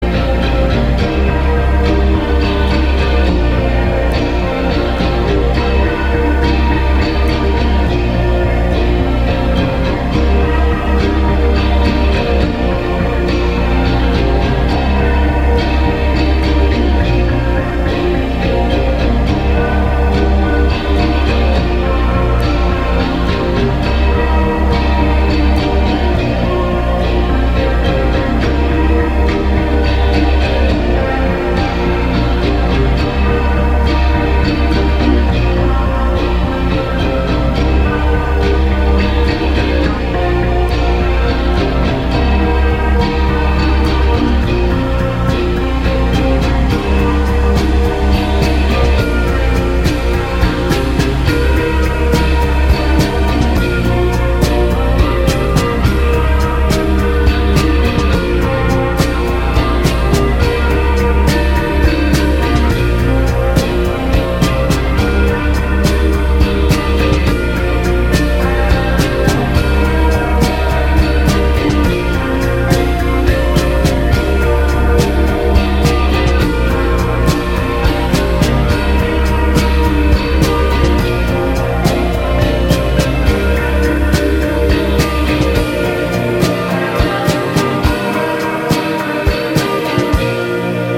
インディ感濃厚なシューゲイズ・アンビエントを披露。